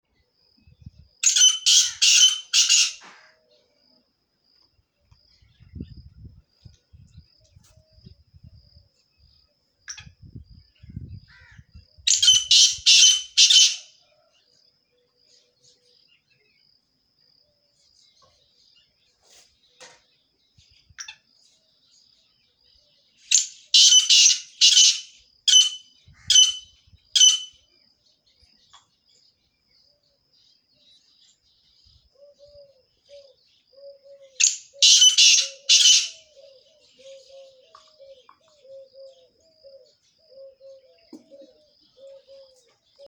Rebhuhn Ruf
• Rebhühner verständigen sich mit kurzen, keckernden Rufen.
Der Ruf klingt wie ein kehliges „kirrik“ oder „krürr“, oft im Morgengrauen.
Rebhuhn-Ruf-Voegel-in-Europa.mp3